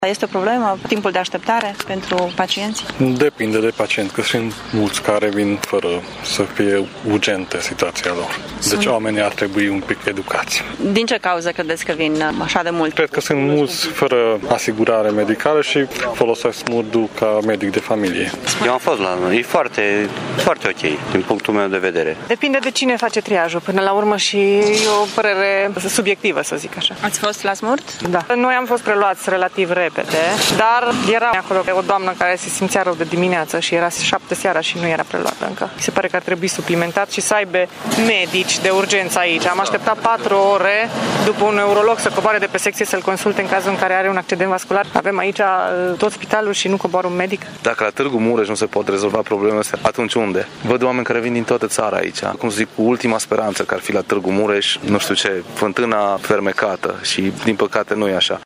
Târgumureșenii care au ajuns la SMURD cu urgențe majore sunt mulțumiți în general de serviciile primite: